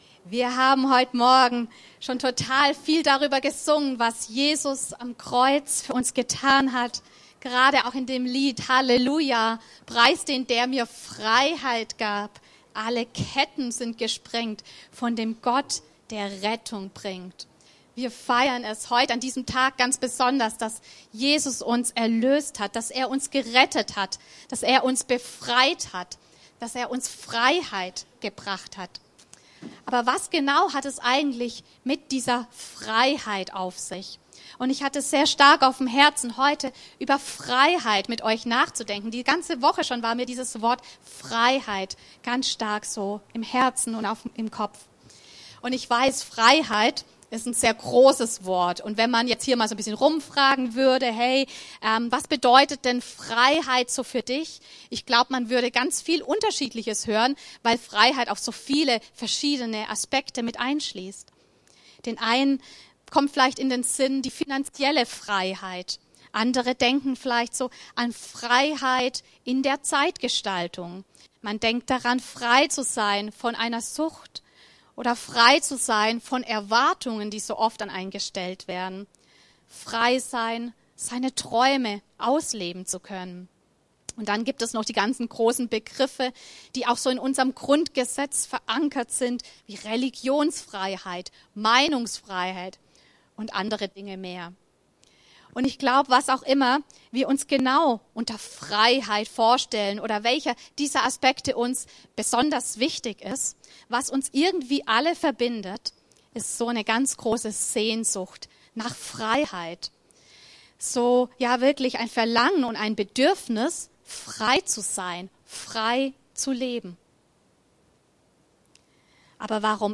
Aktuelle Predigten aus unseren Gottesdiensten und Veranstaltungen